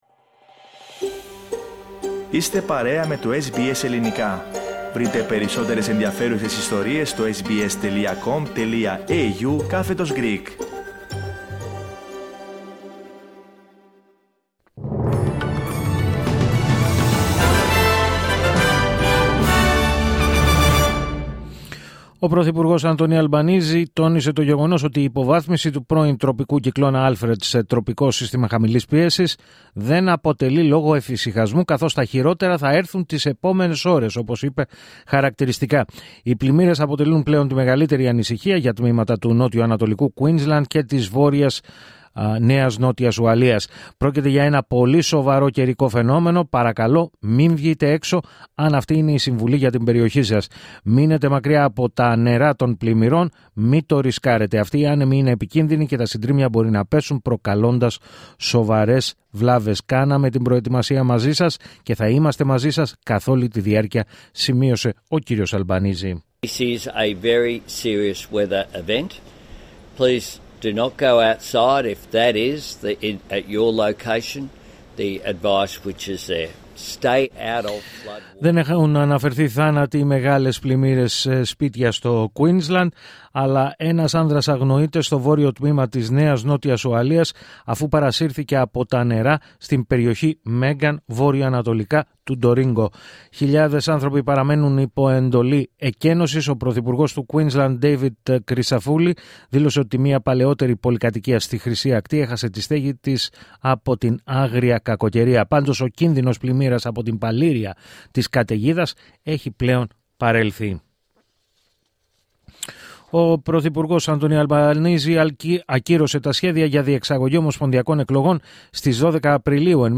Δελτίο Ειδήσεων Σάββατο 8 Μαρτίου 2025